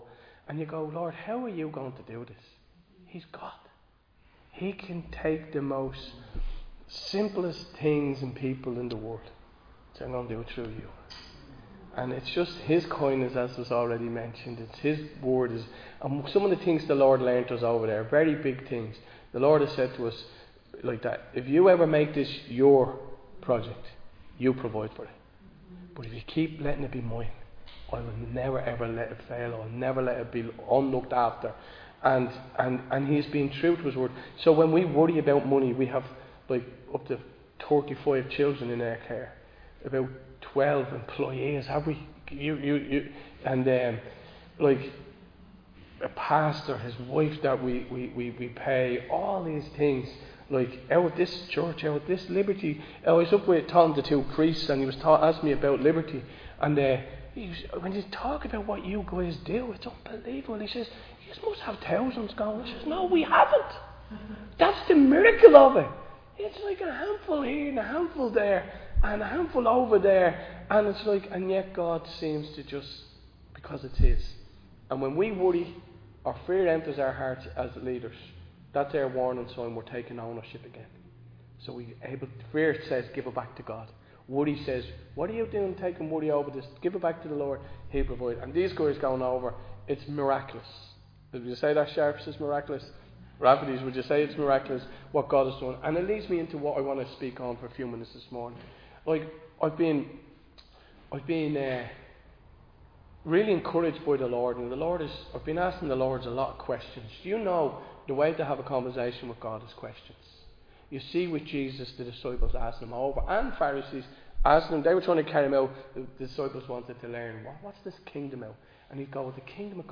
Recorded live in Liberty Church on 6 July 2025